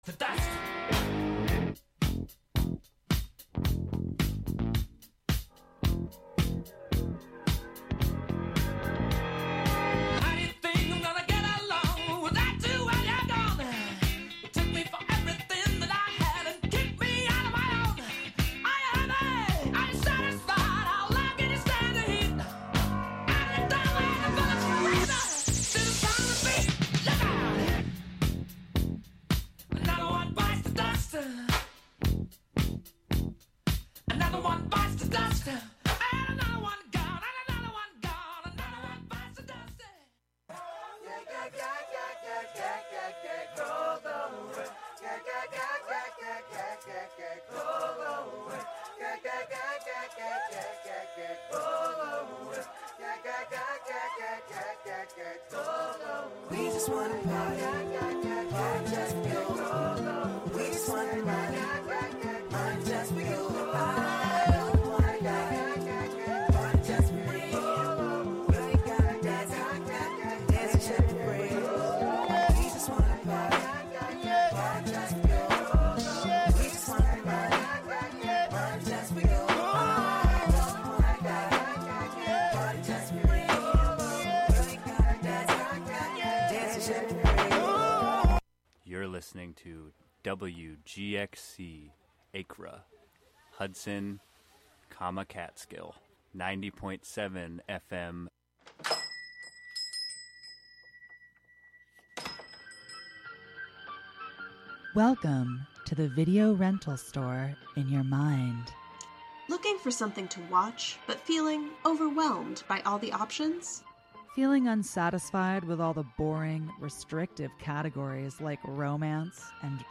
Broadcast from Catskill.